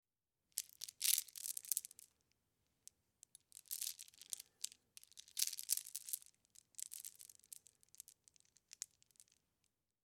На этой странице собраны разнообразные звуки бус: от легкого перекатывания бусин до их мелодичного звона.
Бусы - Альтернативный вариант